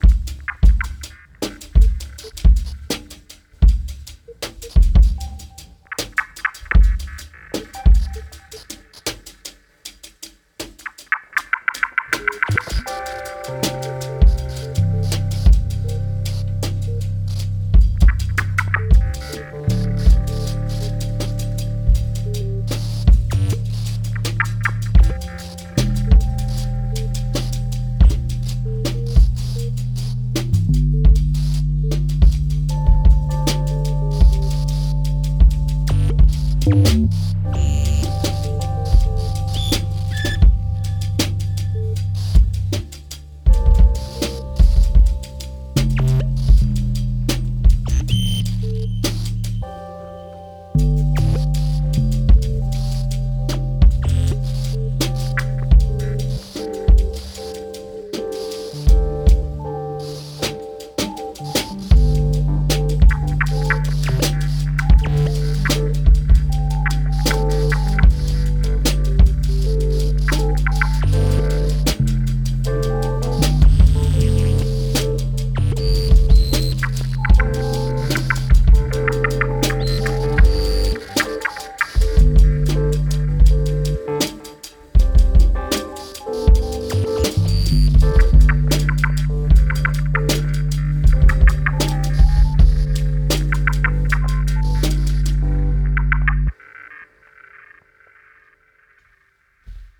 They undertook more voyages exploring noises of live synthesization
drums